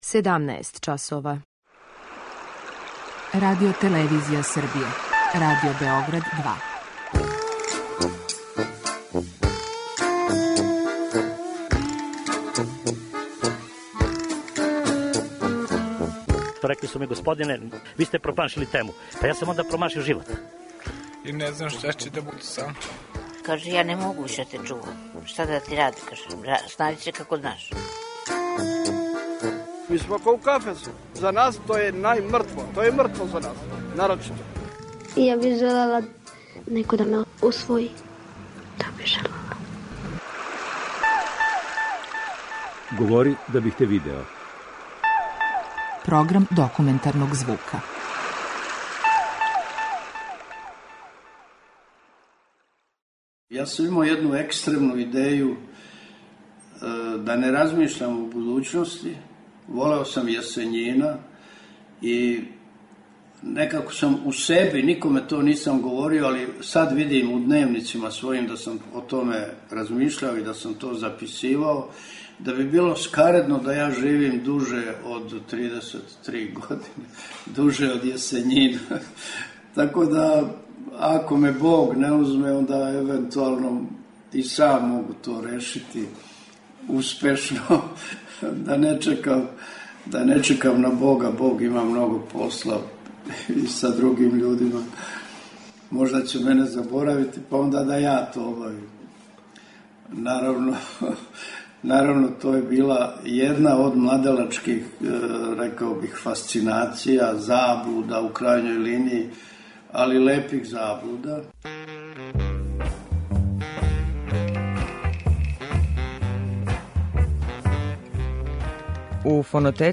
Документарни програм: Тада су стварно дивови ходали земљом
О својим новинарским данима, уреднику Душку Радовићу који није подносио произвољност, о Норвежанину који ником осим њему није дао интервју, о времену у коме је дечја публика била поштована - говори Љубивоје Ршумовић. Чућете документарну емисију Тада су стварно дивови ходали земљом.